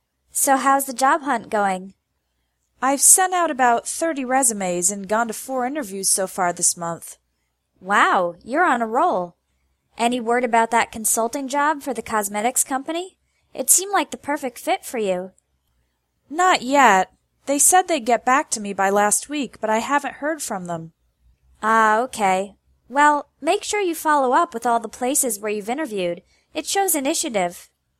English Conversation: Job Hunting